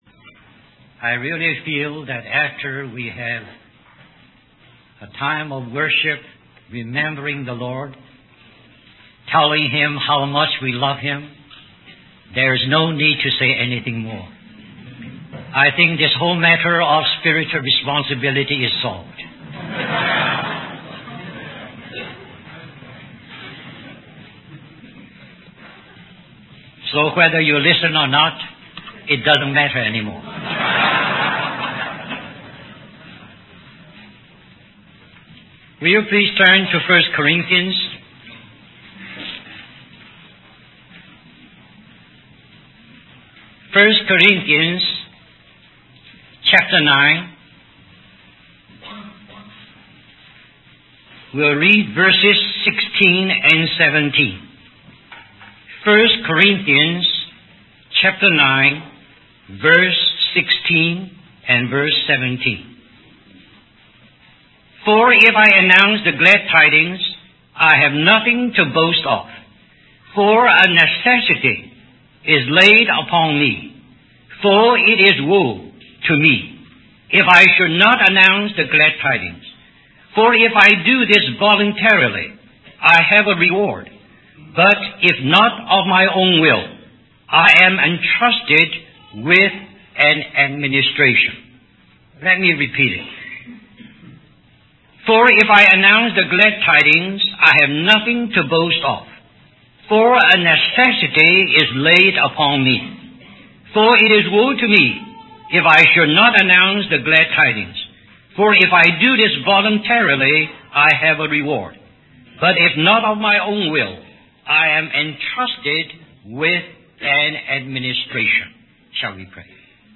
In this sermon, the preacher emphasizes the importance of spiritual responsibility and how it determines our destiny. He uses the parables of the minas, talents, and the good and faithful servant to illustrate this point.